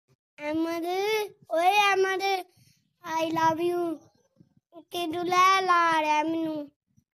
Baby Goat Calling The Name Sound Effects Free Download.
Baby goat calling the name sound effects free download